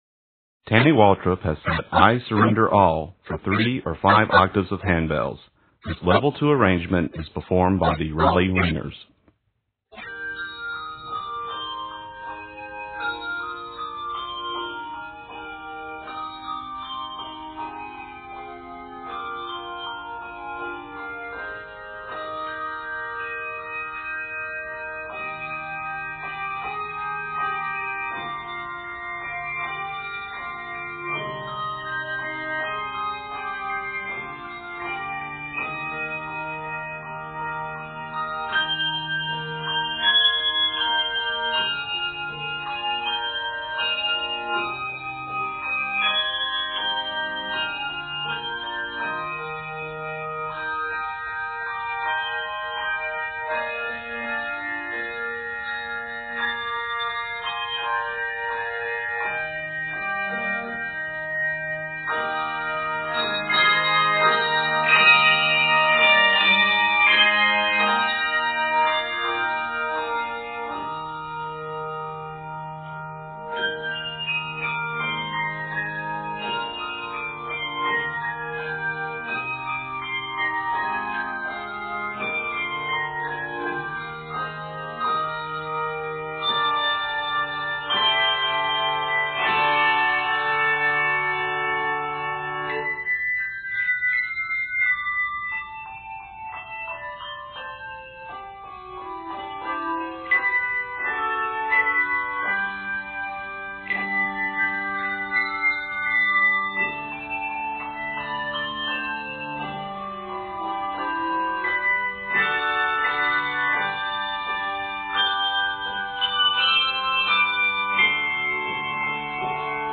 gospel hymn